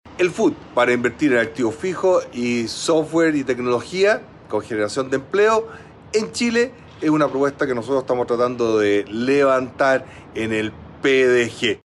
De esta manera, el líder del PDG, Franco Parisi, aseguró que es importante que esto esté centrado en activos fijos de origen nacional y no extranjeros.